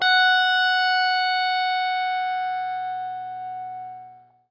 SPOOKY    AW.wav